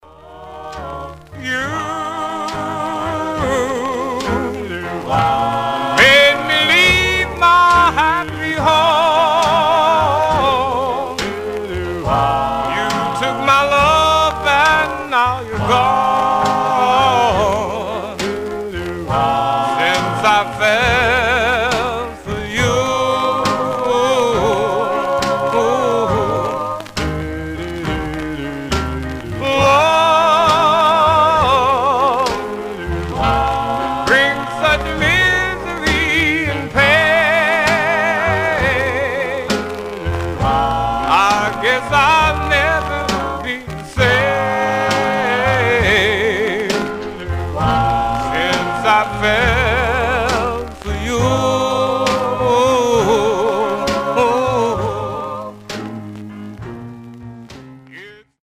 Condition Much surface noise/wear Stereo/mono Mono
Male Black Groups